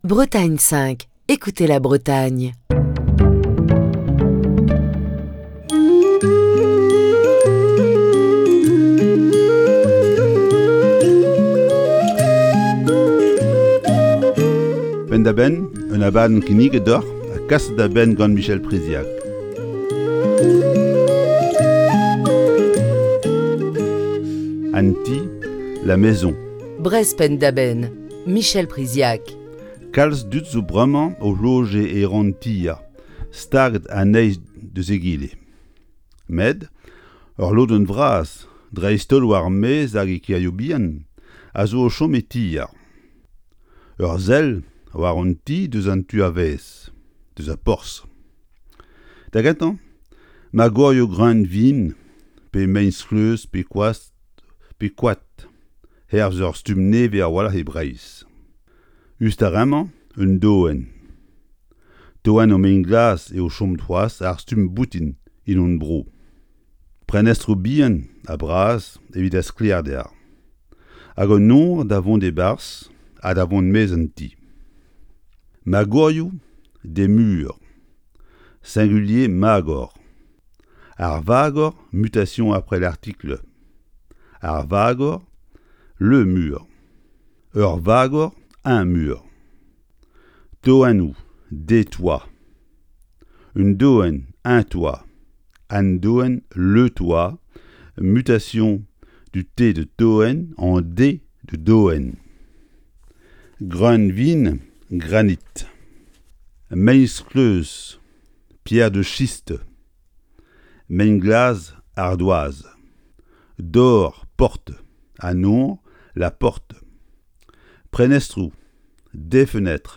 Chronique du 17 mars 2022.